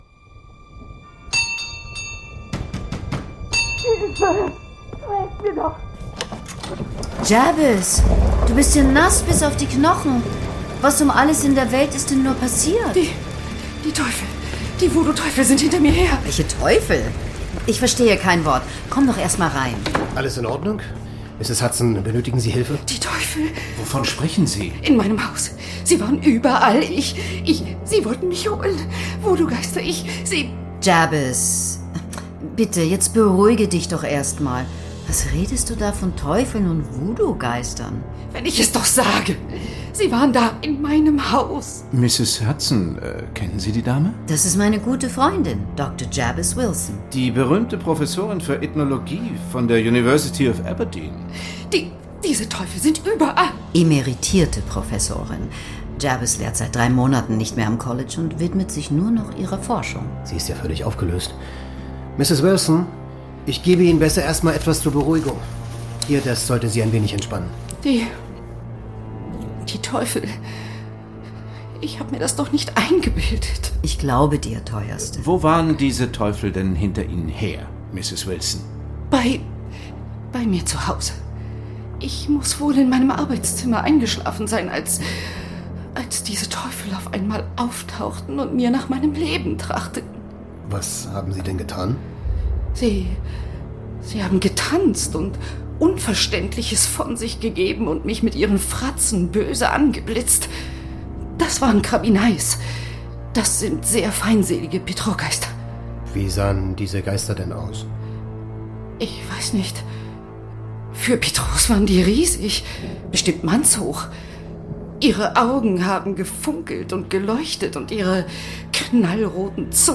Johann von Bülow, Florian Lukas, Peter Jordan (Sprecher)
Hörspiel mit Johann von Bülow, Florian Lukas, Peter Jordan
»Die Hörspiele überzeugen durch rasante Schnitte, feinen Witz und eine bis in die kleinsten Rollen exquisite Sprecherriege.« Süddeutsche Zeitung
»Die Szenerie wirkt lebendig, der Sound ist hochklassig und die Sprecher:innen sind hervorragend.« BÜCHER magazin